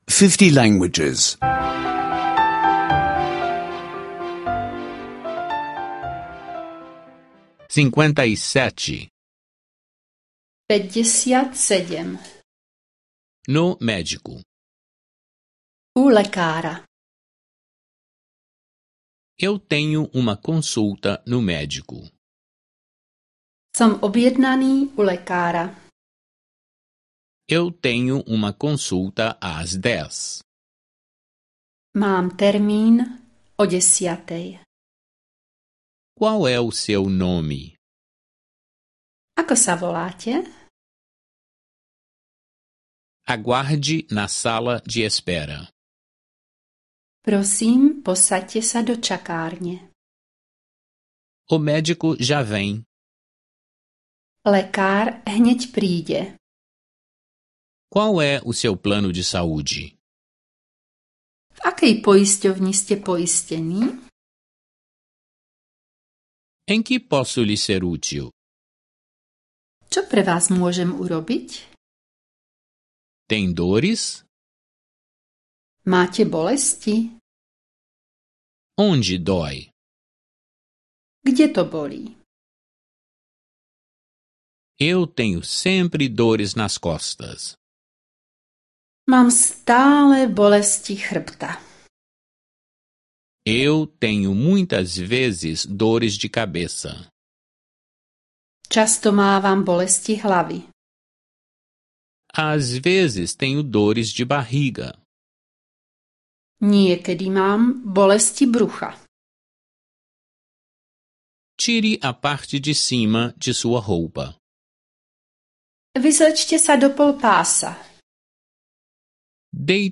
Aulas de eslovaco em áudio — escute online